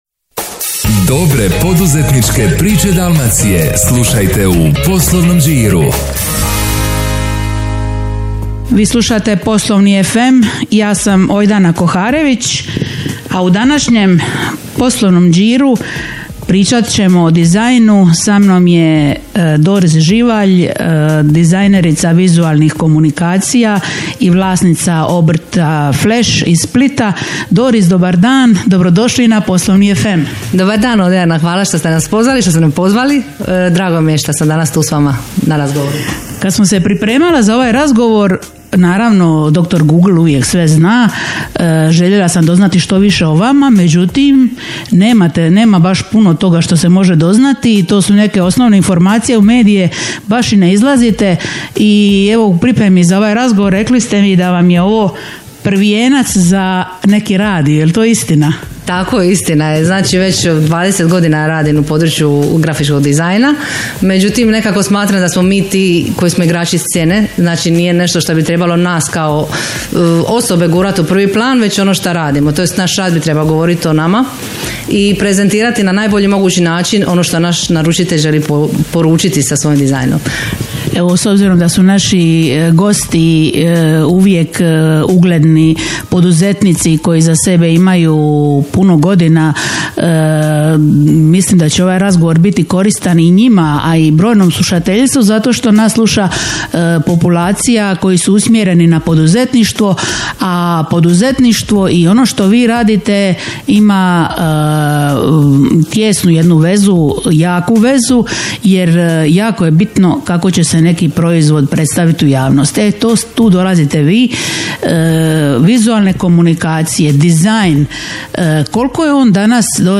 Zvuk dobrog posla! Prvi poslovni radio te prvi poslovni podcast internet radio (e-radio) u ovom dijelu Europe.